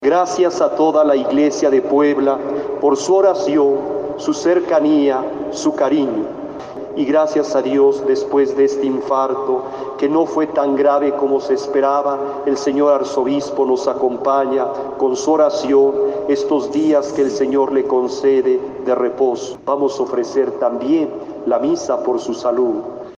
Infarto del arzobispor "no fue tan grave" - Felipe Pozos, obispo auxiliar de Puebla